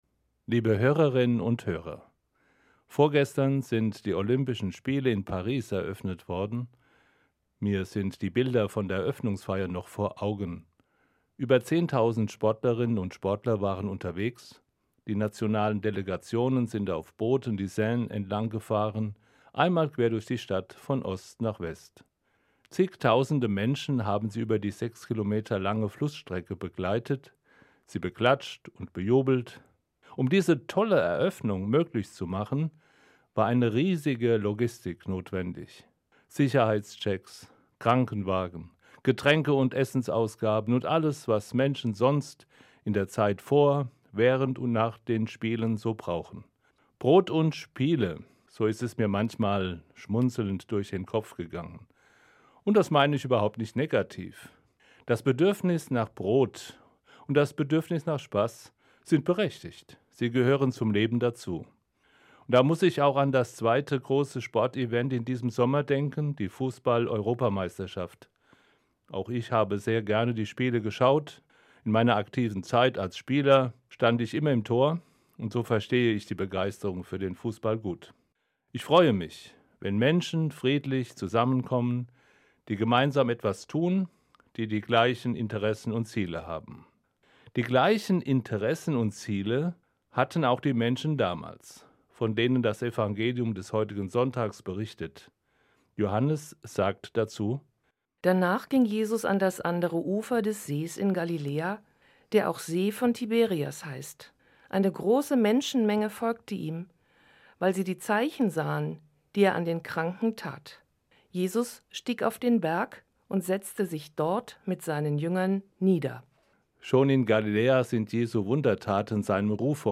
Eine Sendung von Prof. Dr. Karlheinz Diez, Katholischer Weihbischof, Fulda